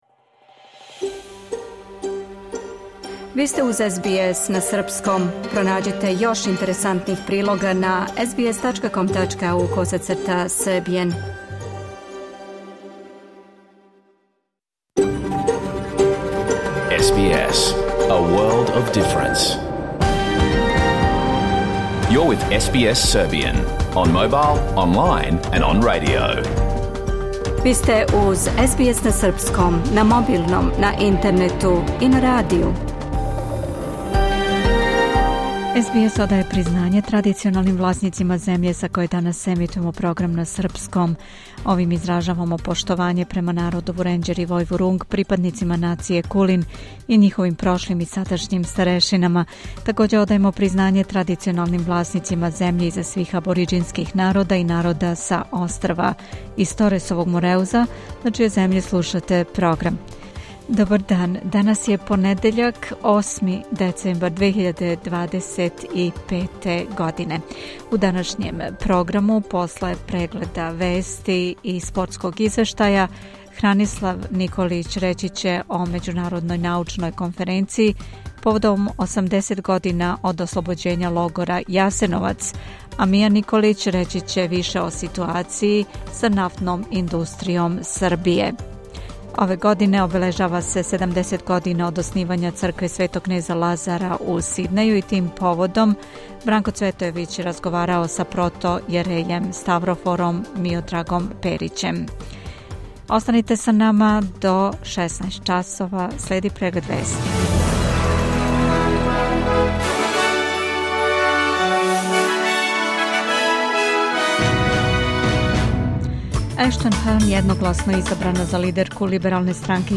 Програм емитован уживо 4. децембра 2025. године